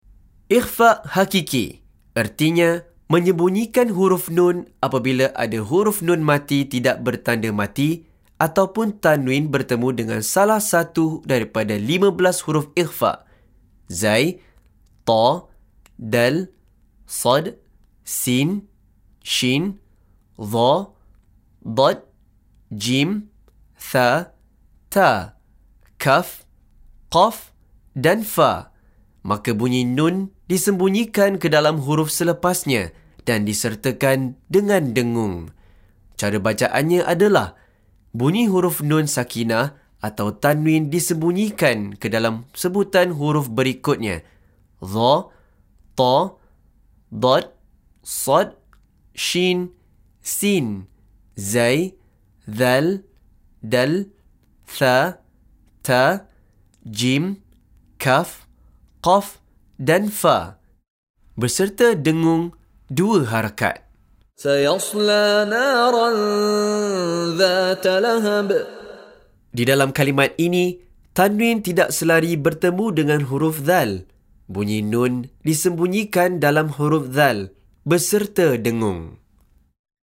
Penerangan Hukum + Contoh Bacaan dari Sheikh Mishary Rashid Al-Afasy
DISEMBUNYIKAN/SAMARKAN sebutan huruf Nun Sakinah/Tanwin tidak Selari beserta dengung 2 harakat.